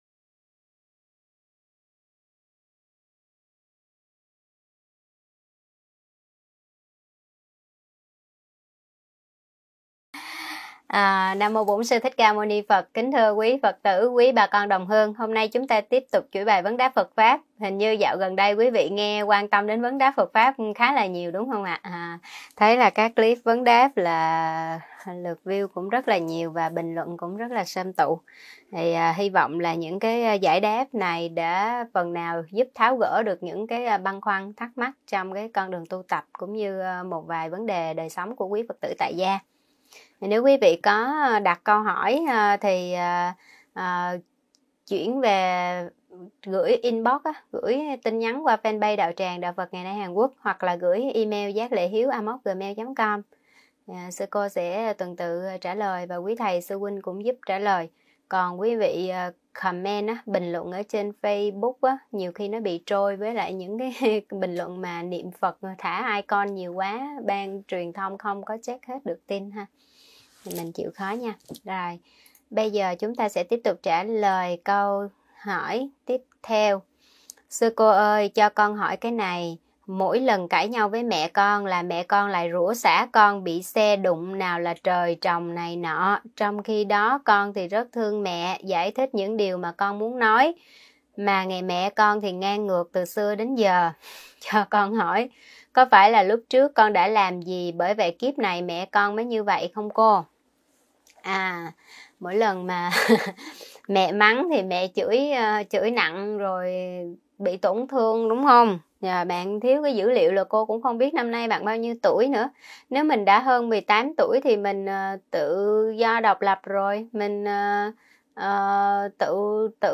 Vấn đáp: Tâm sân quá nặng, không làm chủ được bản thân